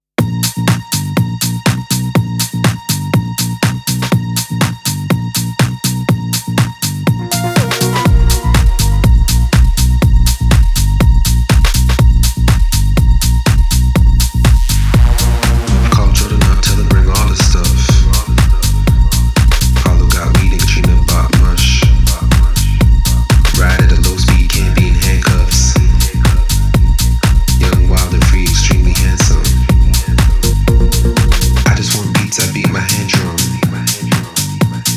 Жанр: Танцевальные / Русские